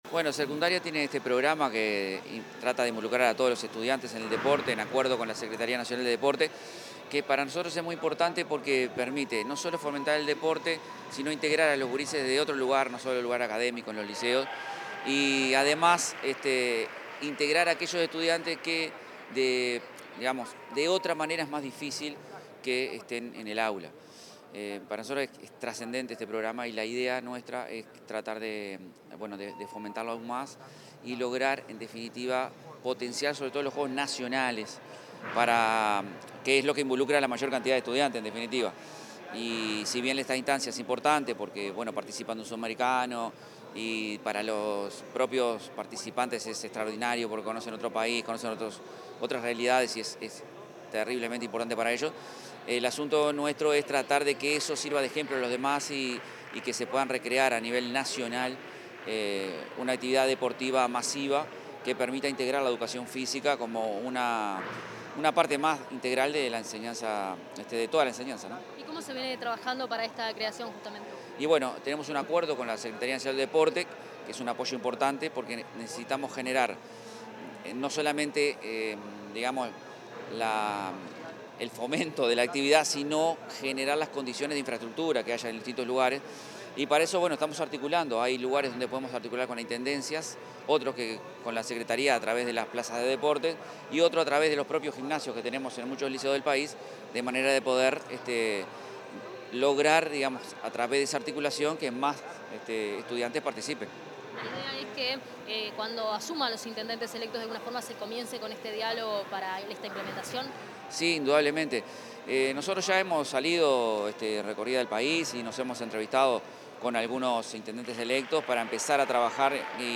Declaraciones del director general de Educación Secundaria, Manuel Oroño
Declaraciones del director general de Educación Secundaria, Manuel Oroño 13/06/2025 Compartir Facebook X Copiar enlace WhatsApp LinkedIn En el marco del reconocimiento a estudiantes de enseñanza media que participaron en los Juegos Sudamericanos Escolares en Colombia en 2024, el director general de Educación Secundaria, Manuel Oroño, realizó declaraciones a la prensa.